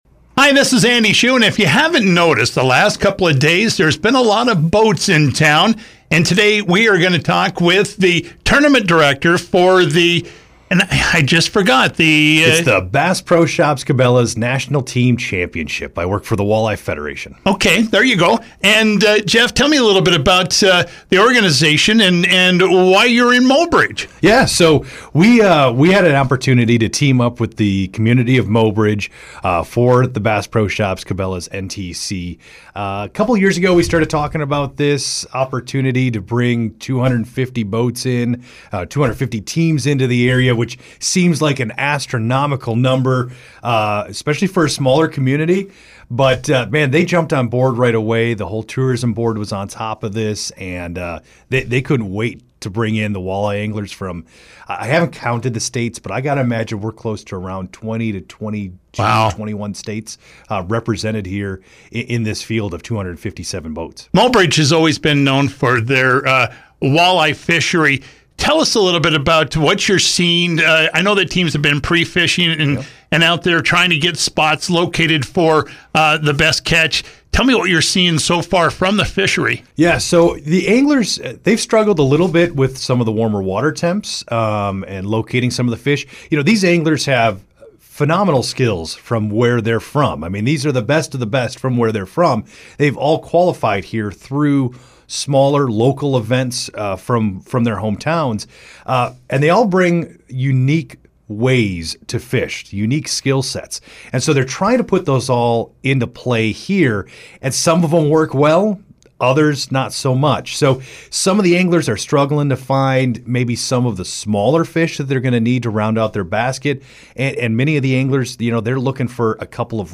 pro-walleye-interview.mp3